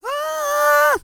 E-CROON 3027.wav